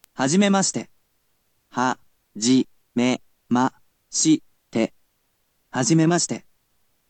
You’ll be able to hear an organic voice in another resource, but for now, he’ll definitely help you learn whilst at least hearing the words and learning to pronounce them.